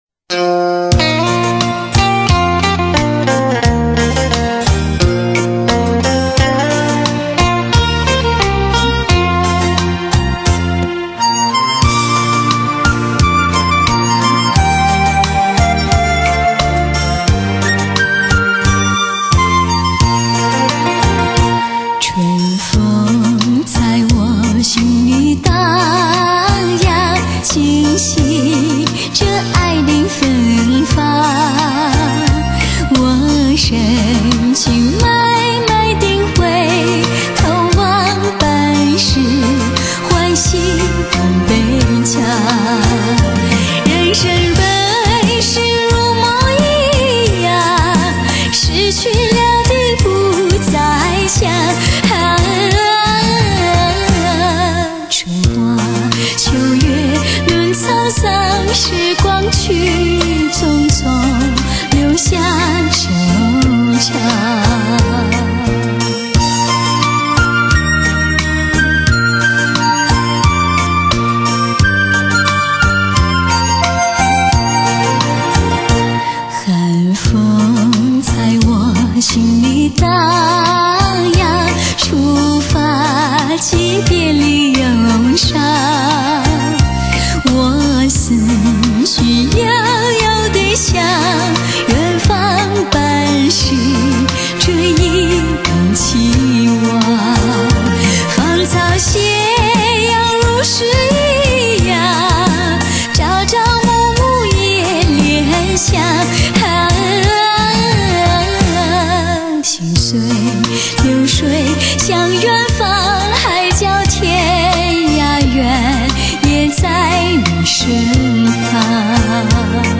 清丽空灵的音乐氛围、纯净自然的演唱中潜透着火一般的热情魅力，美得清澈透明、 让人心醉的旋律中又处处彰显古代优雅情怀